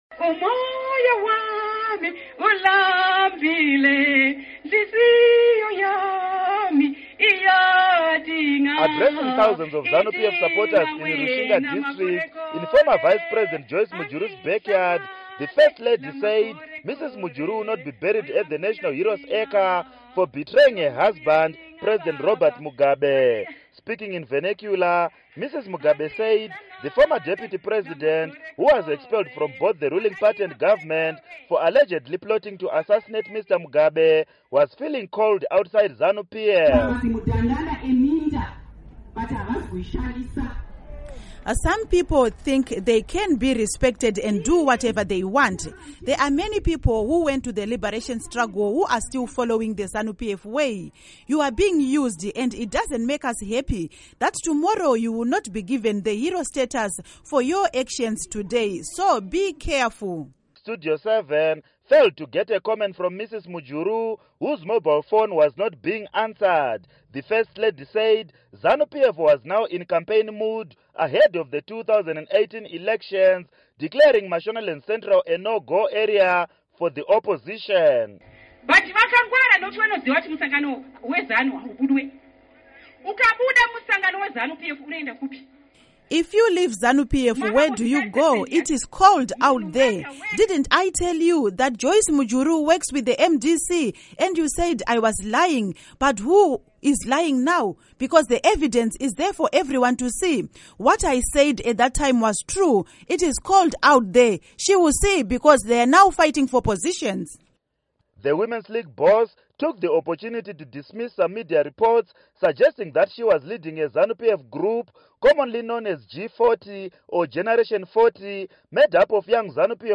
Report on Grace Mugabe Rally